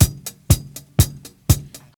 • 121 Bpm Classic Drum Loop Sample G Key.wav
Free breakbeat sample - kick tuned to the G note. Loudest frequency: 3571Hz
121-bpm-classic-drum-loop-sample-g-key-pA1.wav